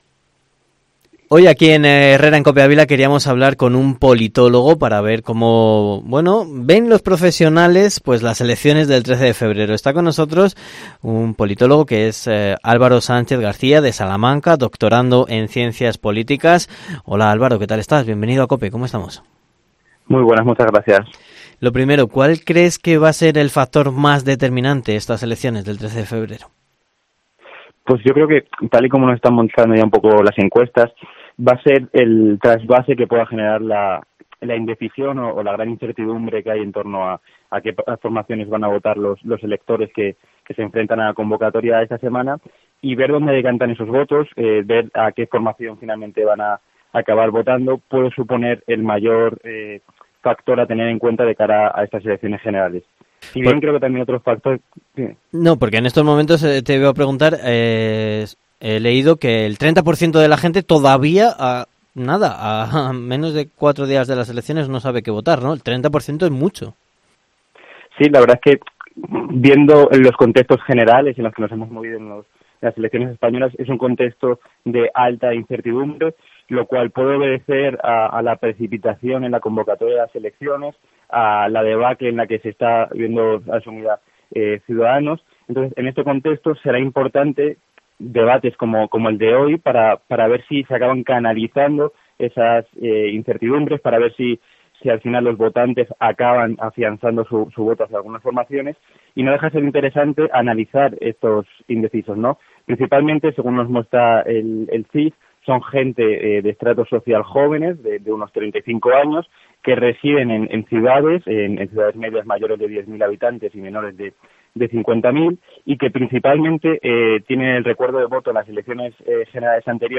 (ESCUCHAR ENTREVISTA) Entre los partidos políticos